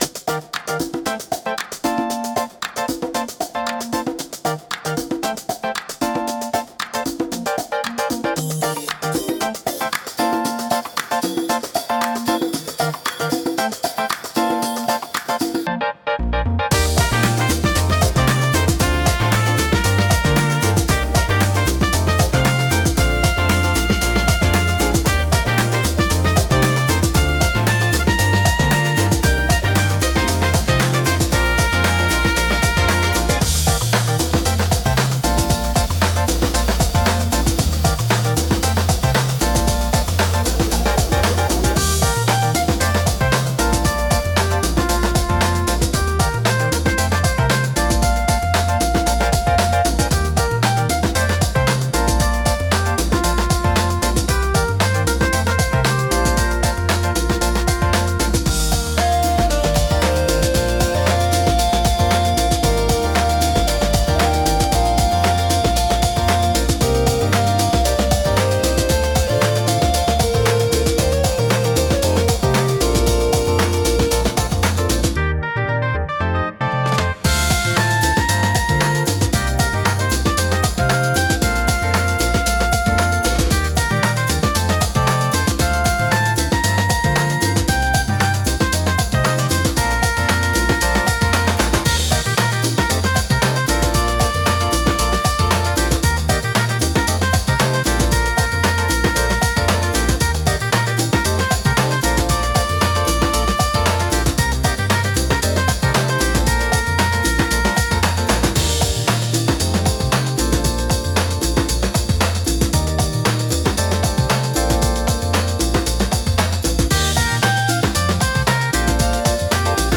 親しみやすく軽快な空気感を演出したい場面で活躍します。